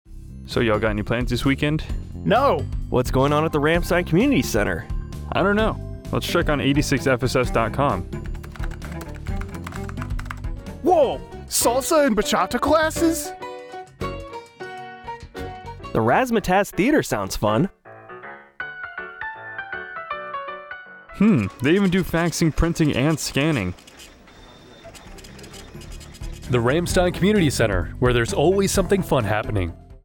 Radio Spot - Ramstein Community Center
A 30-second radio spot about the Ramstein Community Center that will air on AFN Kaiserslautern from Aug. 1, 2024, to Aug. 1, 2025.